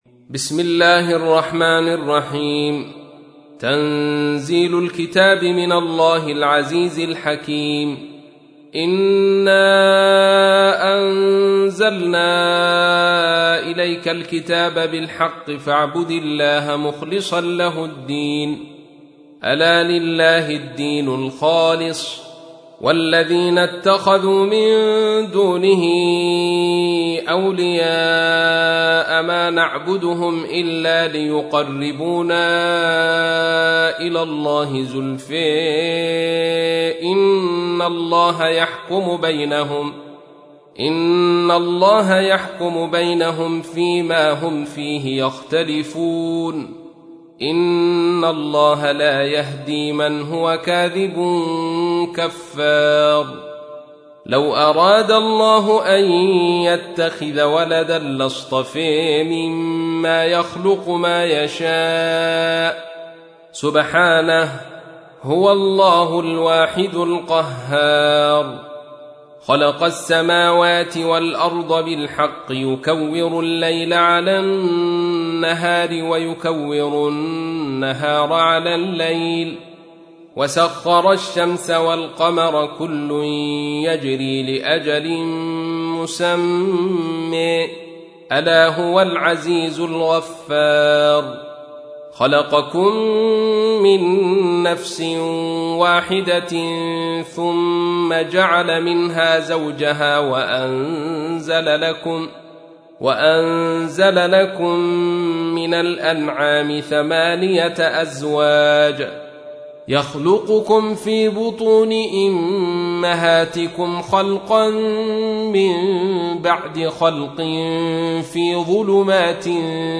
تحميل : 39. سورة الزمر / القارئ عبد الرشيد صوفي / القرآن الكريم / موقع يا حسين